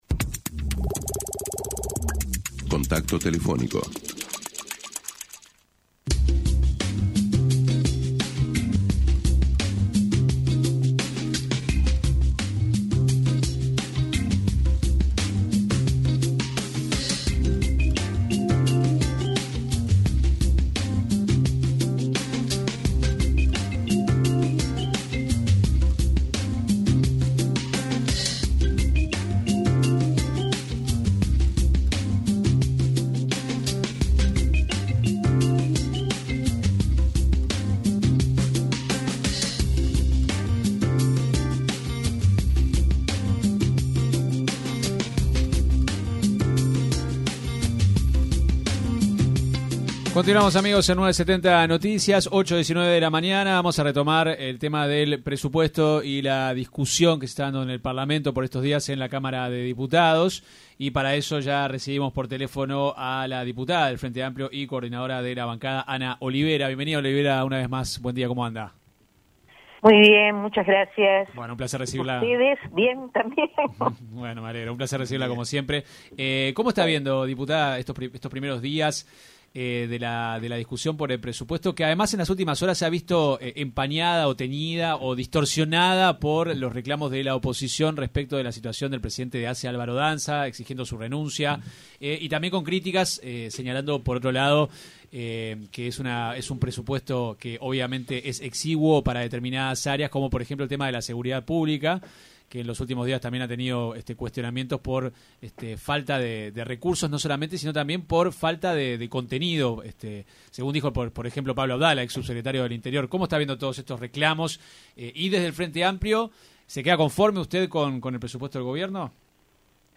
La diputada por el Frente Amplio, Ana Olivera, se refirió en diálogo con 970 Noticias al presupuesto que se asignó a la Educación en el proyecto de ley enviado por el Ejecutivo.